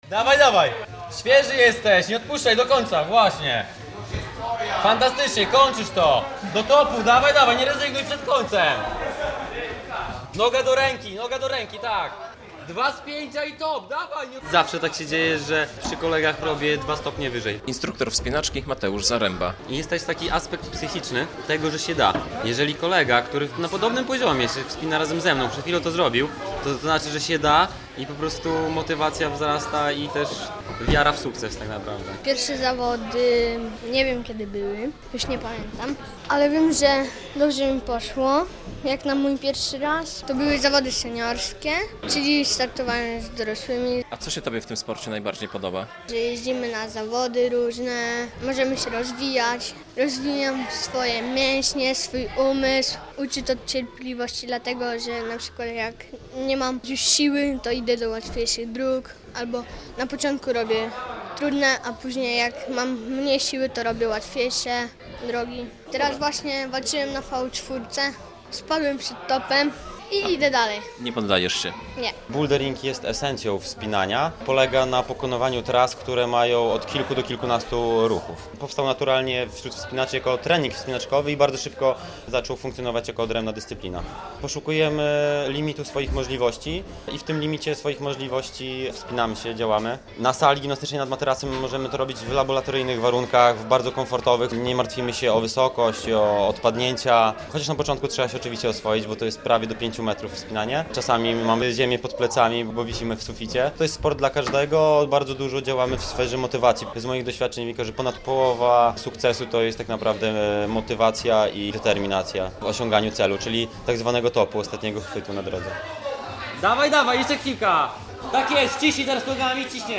Bouldering, czyli wspinaczka na niewielkich wysokościach - relacja